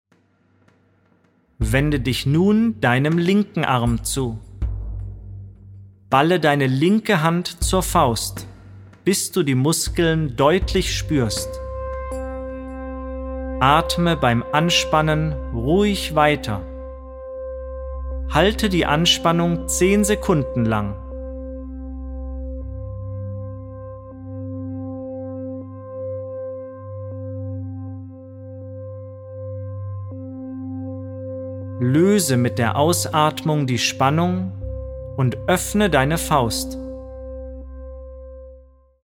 Meditationen und Entspannungsübungen gegen Schmerzen
Inhalt: Die CD enthält 6 Phantasiereisen, Meditationen, Muskelentspannungsübungen und Visualisierungen zur positiven Unterstützung bei Schmerzen